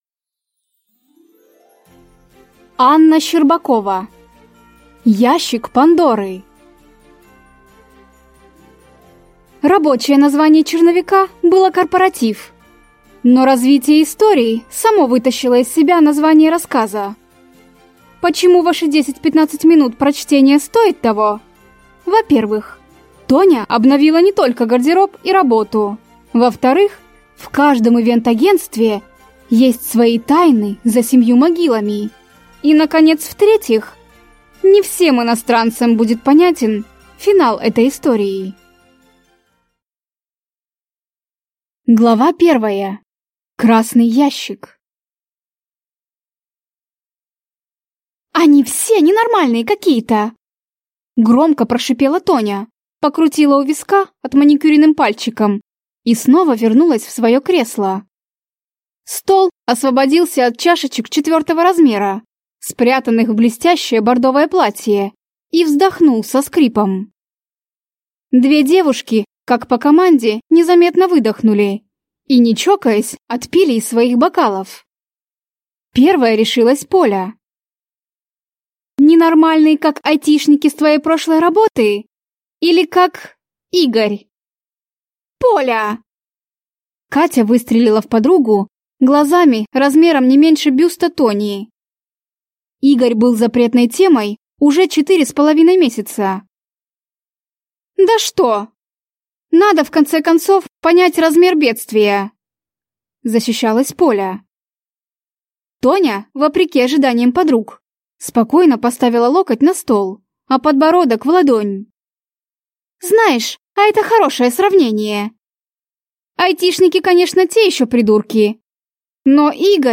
Аудиокнига Ящик Пандоры | Библиотека аудиокниг